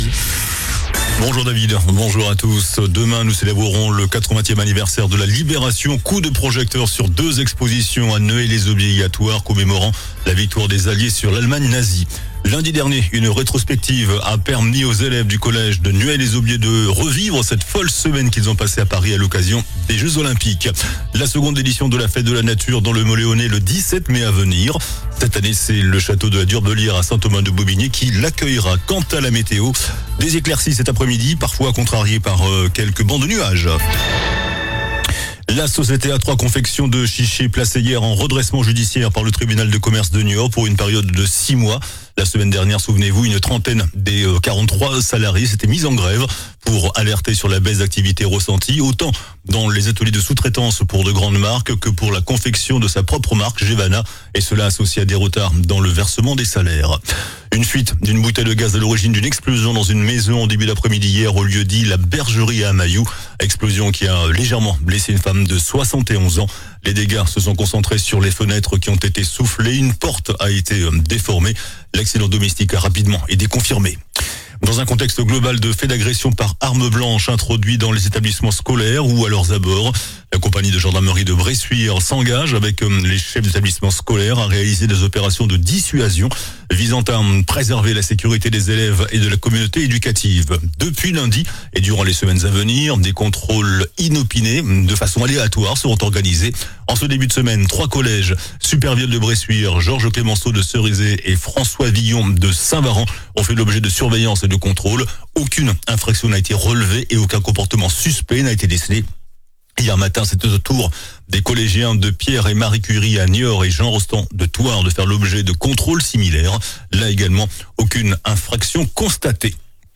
JOURNAL DU MERCREDI 07 MAI